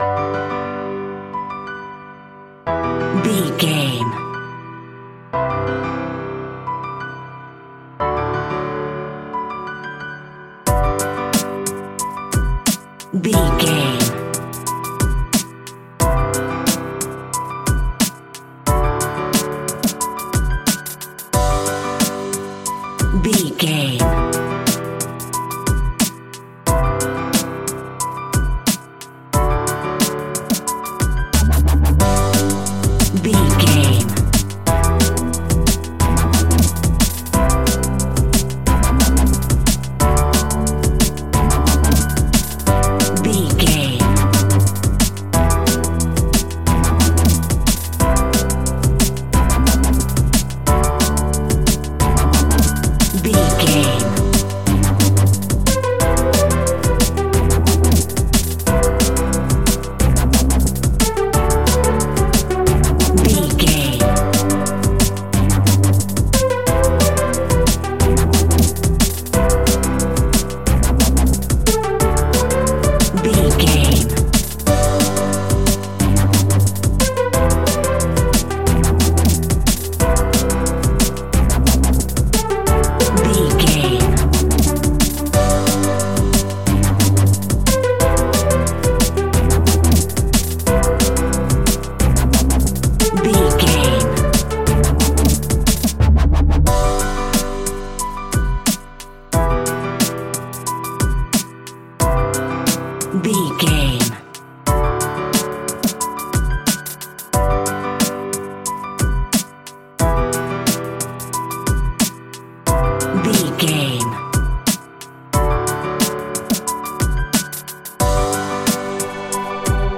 Ionian/Major
Fast
uplifting
lively
futuristic
hypnotic
industrial
frantic
drum machine
synthesiser
electronic
sub bass
synth leads
synth bass